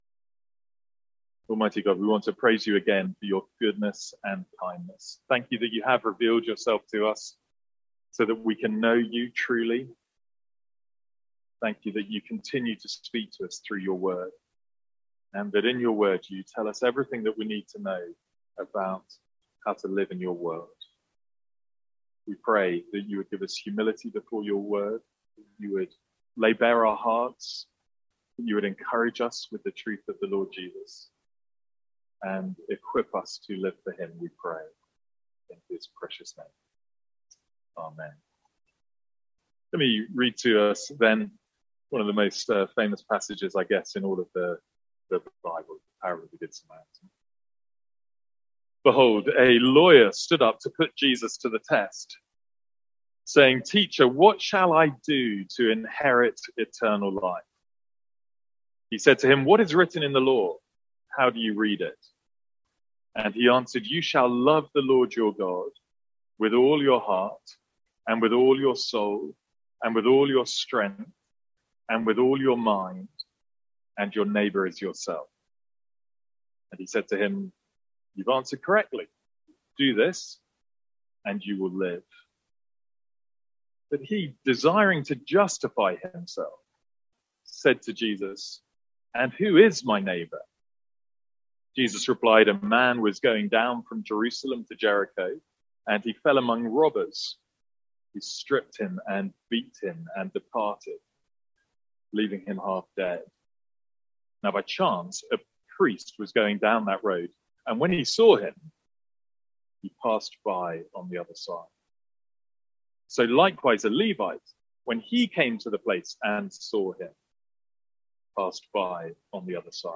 Sermons | St Andrews Free Church
From our morning series in Loving God.